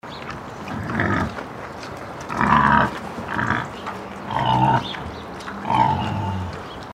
Si cliqueu a l’audio, sentireu el grunyit del porc senglar